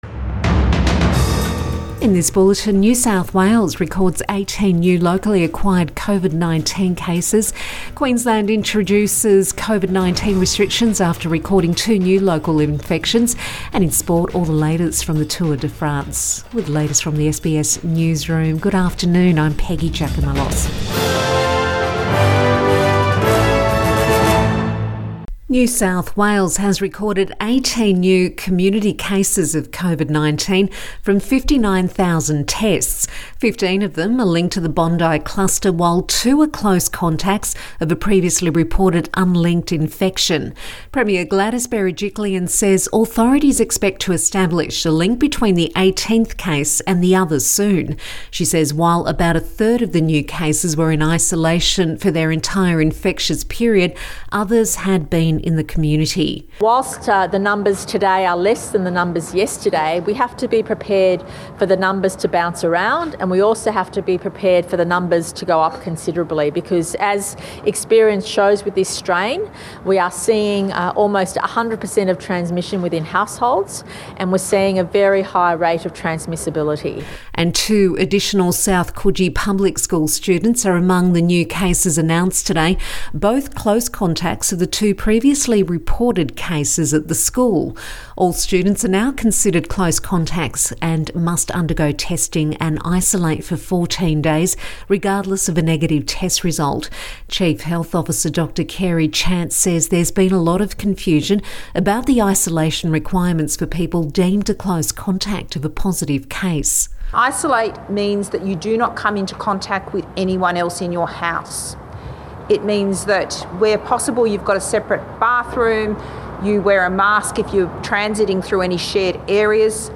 Midday bulletin June 28 2021